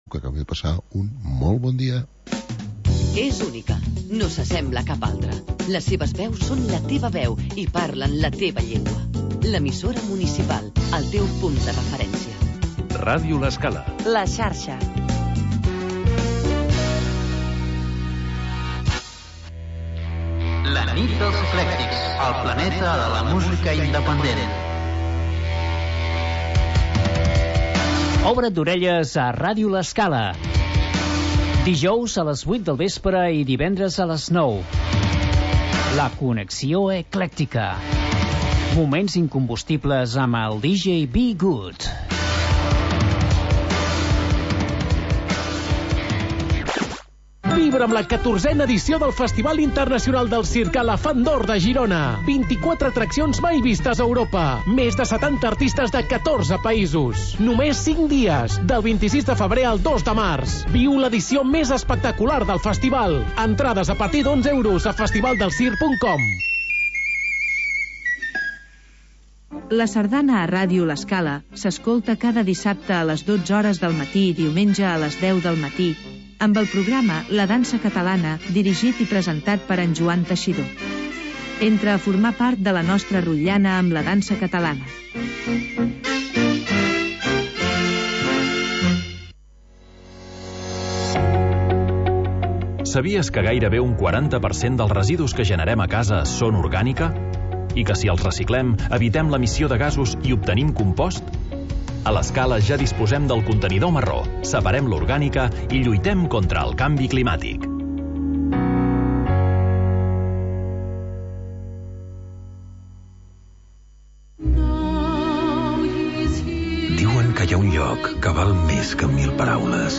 Magazín d'entreteniment per acompanyar el migdia